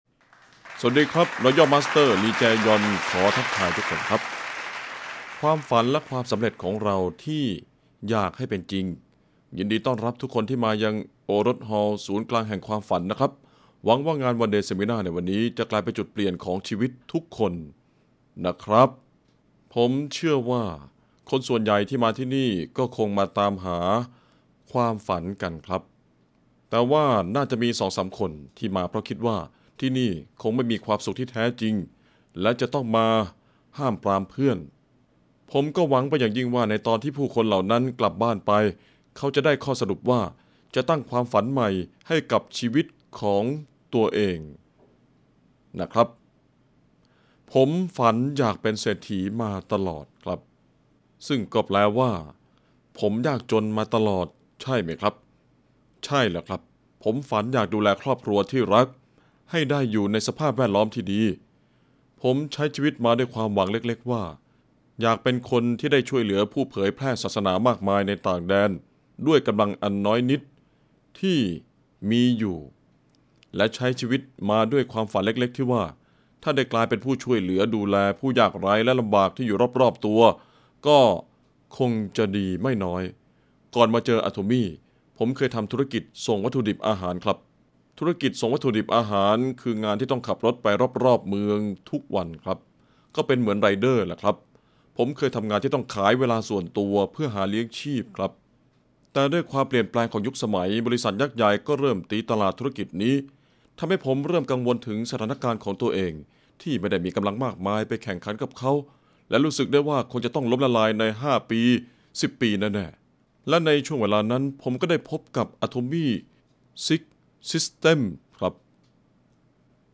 กล่าวต้อนรับ